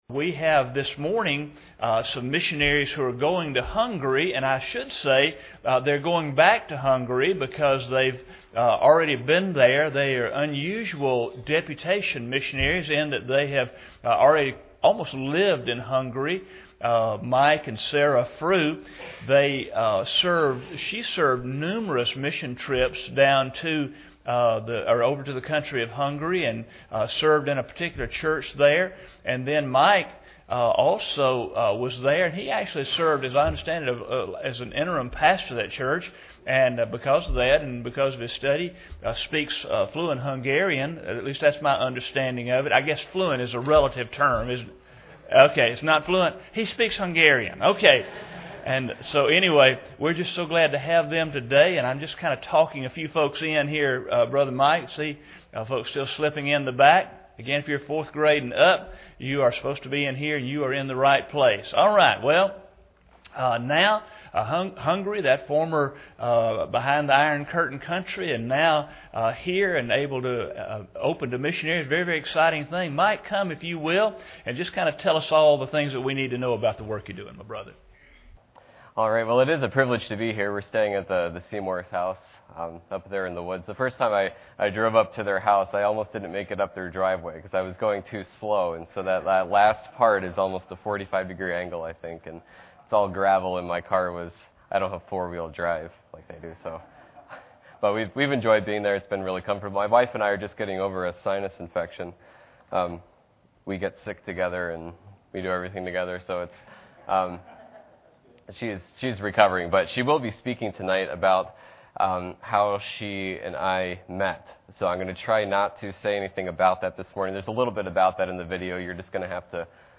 Service Type: Sunday School Hour